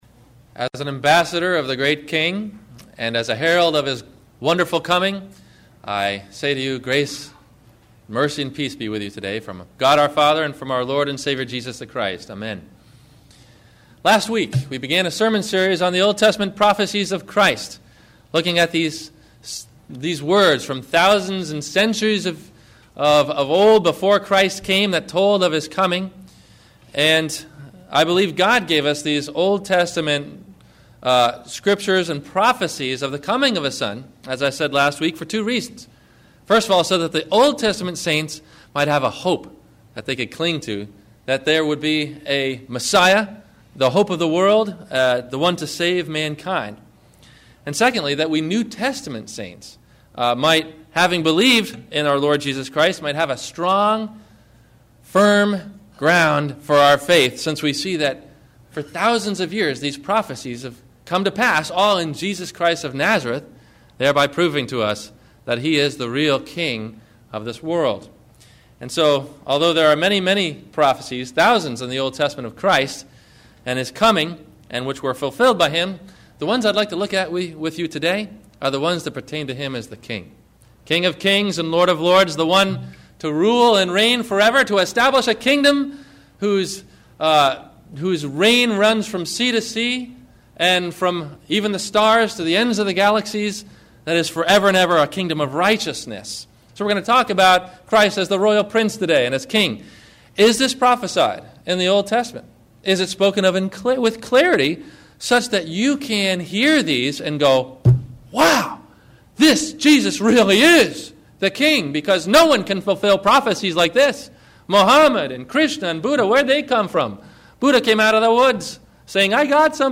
The Throne of David – the Promise of a King – Sermon – December 14 2008